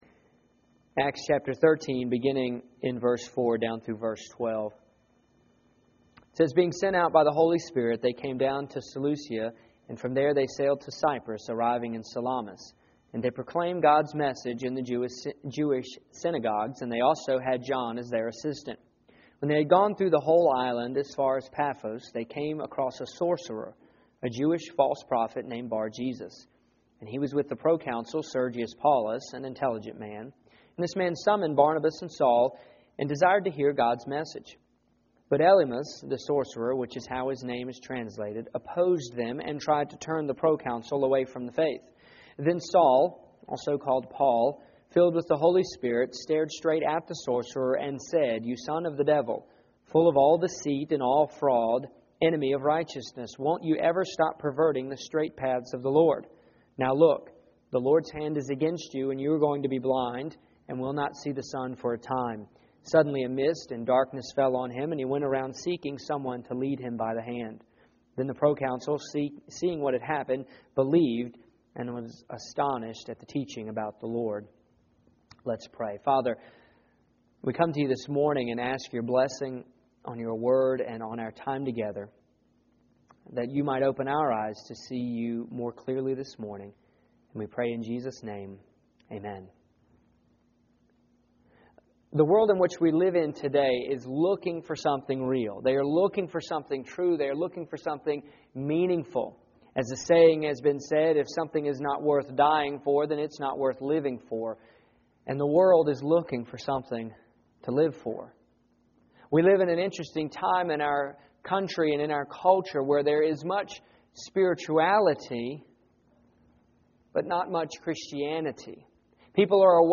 Here is my sermon from 8-17-08.